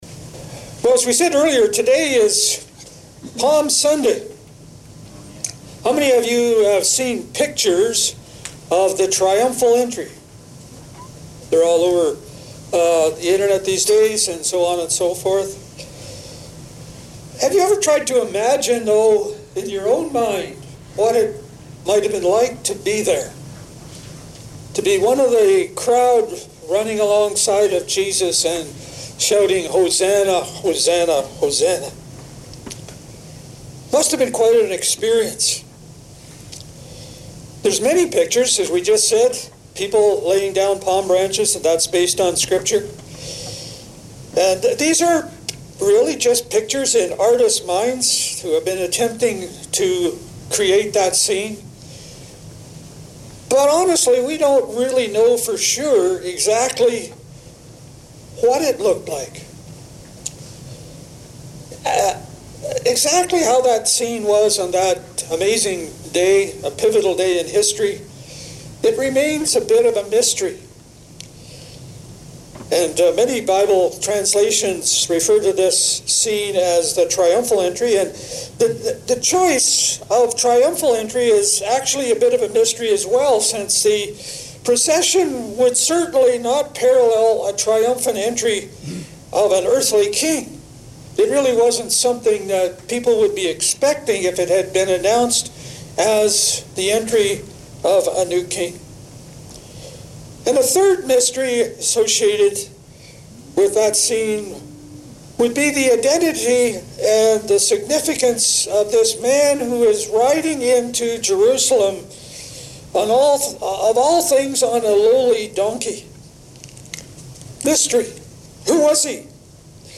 Sermons | Chesley Community Church